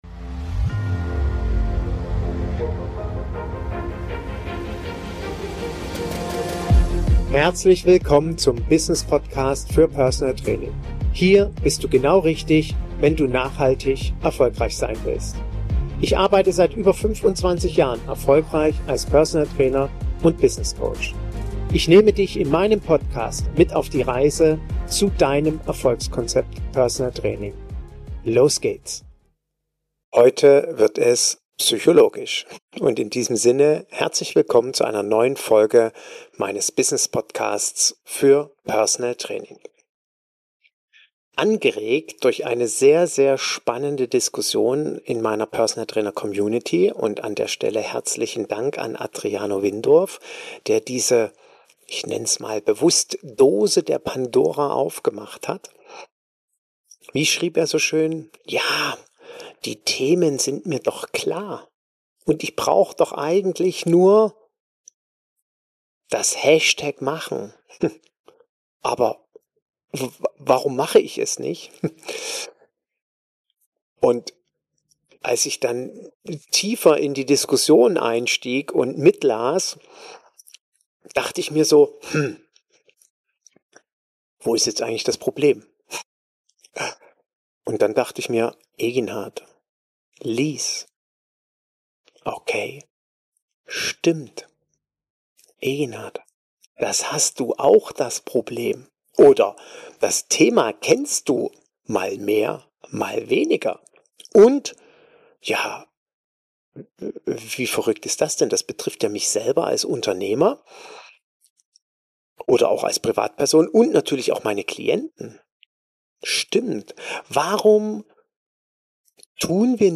In dieser Folge spreche ich mit Yogalehrerin und Ayurveda Coach